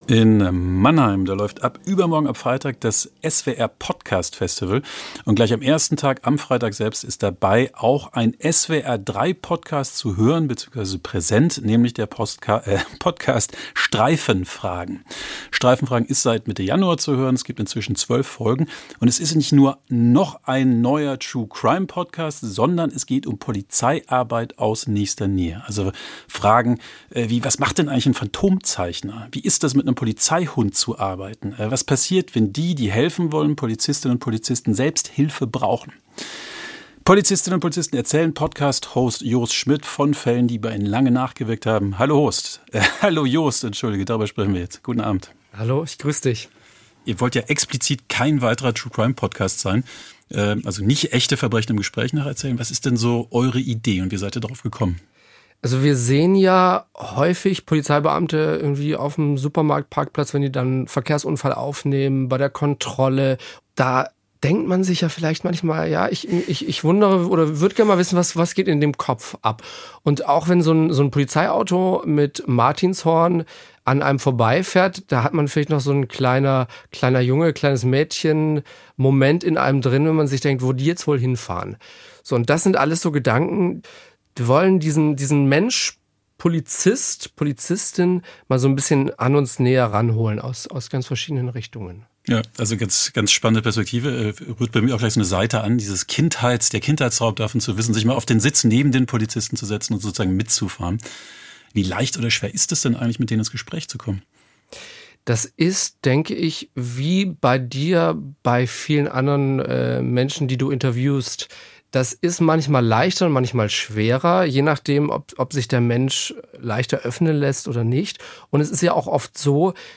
Das Interview führte